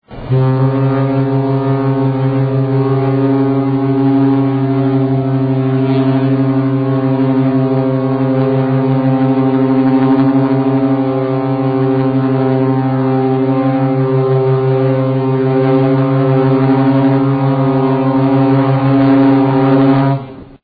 The ship again did slow S-turns and sounded its horn to honor the servicemen who gave their all for their country.
Click the picture to hear the horn.